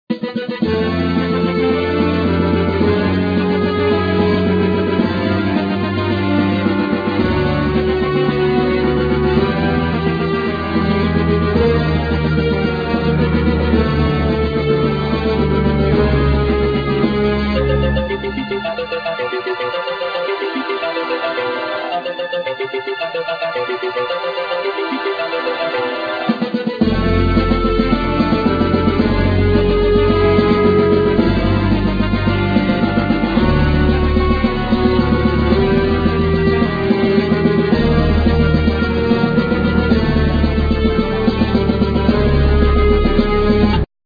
Guitar,Keyboard,Sequencer
Violin,Viola
Drums,Percussion
Clarinett
Double Bass
Cello
Flute